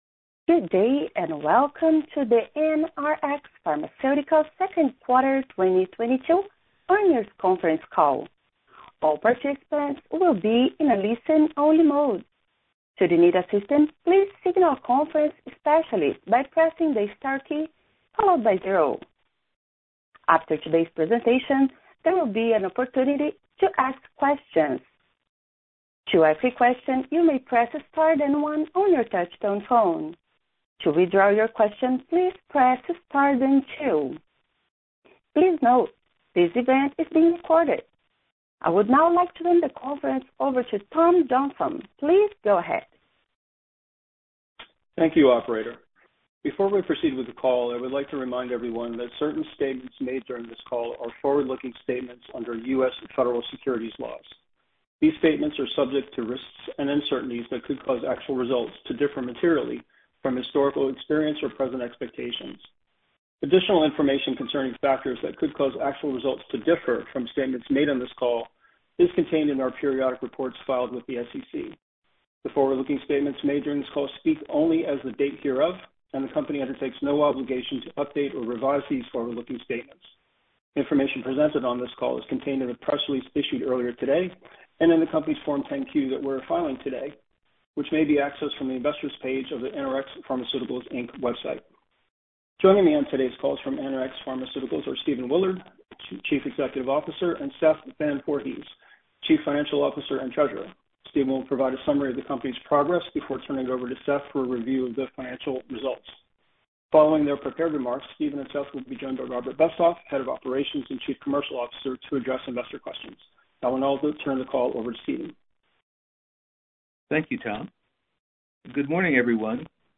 Listen to Earnings Call